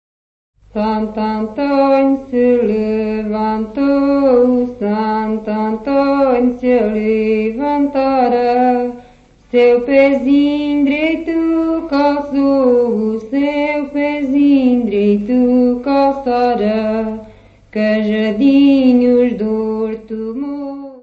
Notes:  Recolha de Michel Giacometti, pertencente aos arquivos do Ministério da Cultura; A ordem das faixas 16 e 17 está trocada no folheto (cf. as notas de conteúdo); Disponível na Biblioteca Municipal Orlando Ribeiro - Serviço de Fonoteca
Music Category/Genre:  World and Traditional Music